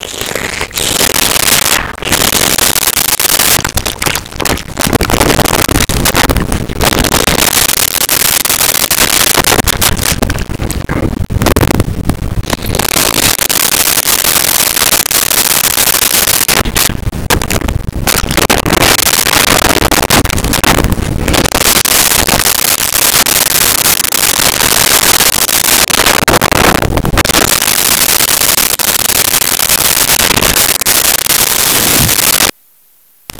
Monster Slobber
Monster Slobber.wav